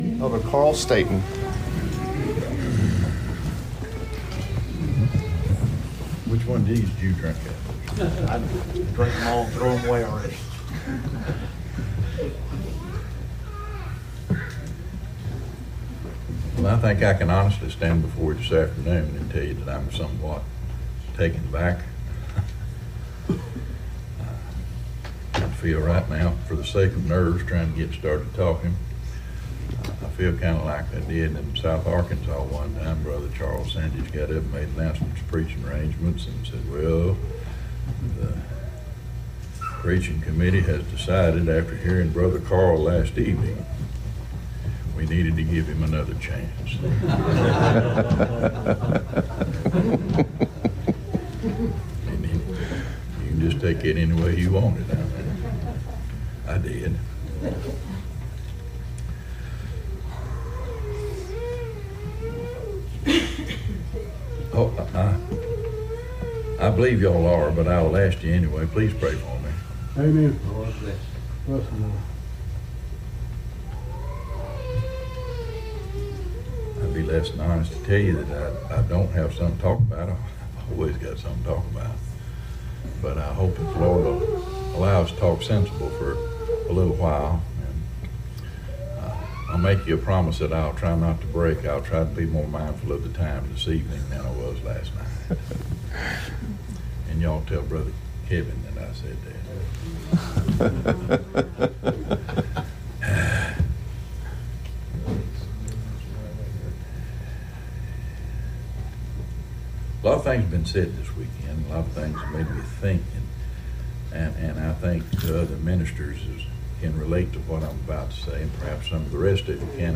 Little River August 2023 Meeting